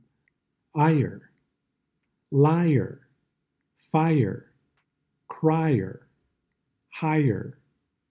Lesson 5 – “R” vowels /ɝ/, /ɚ/, /ɑr/, /ɜr/, /ɪr/, /ɔr/ – American English Pronunciation